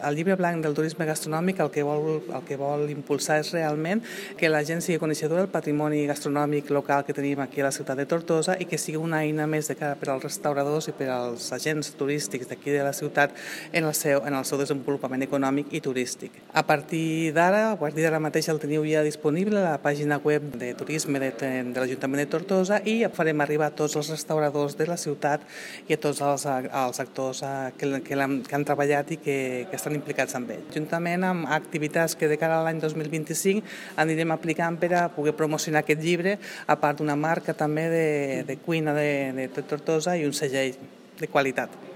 Sònia Rupérez, regidora de turisme de tortosa, destaca que la publicació està a disposició de tothom que el vulgui consultar a través del web de turisme de Tortosa…